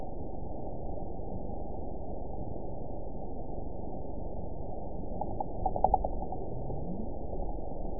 event 912124 date 03/18/22 time 23:29:43 GMT (3 years, 1 month ago) score 9.16 location TSS-AB05 detected by nrw target species NRW annotations +NRW Spectrogram: Frequency (kHz) vs. Time (s) audio not available .wav